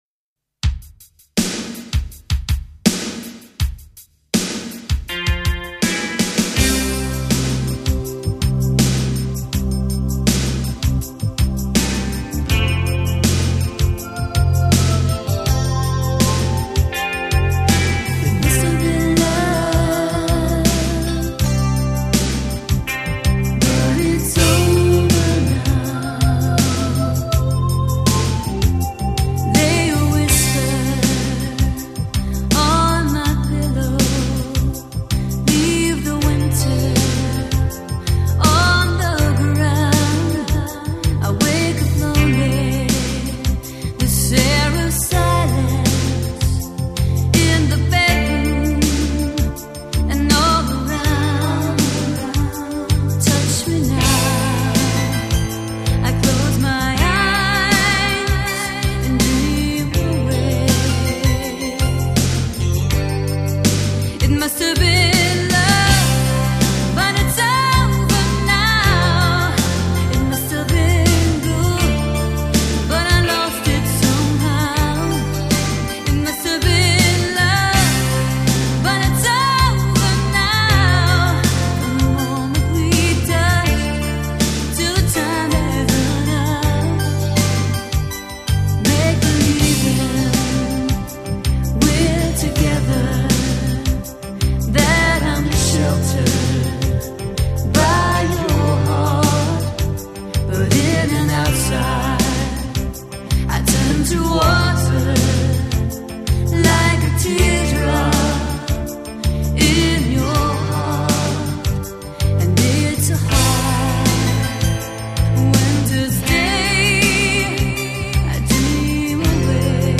经典情歌浪漫极致演绎，绝美音色细腻缠绵发烧天碟，精心打造的极品音乐。